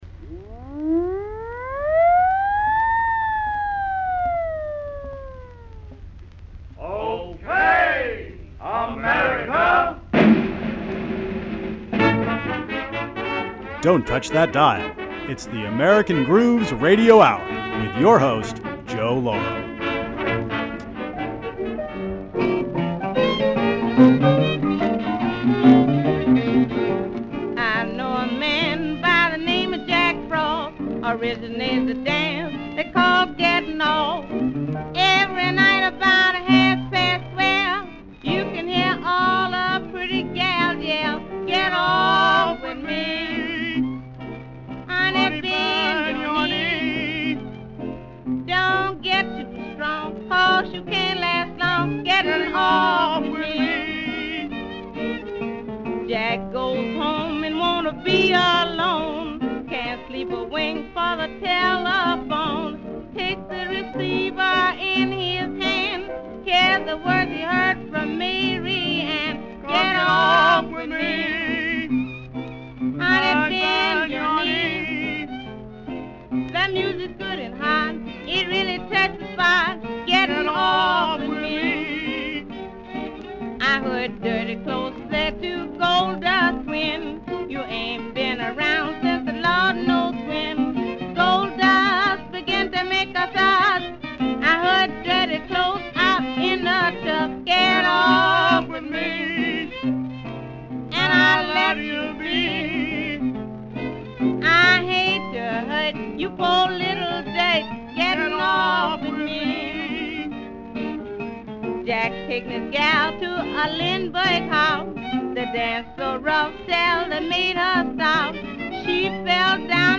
JAUNTY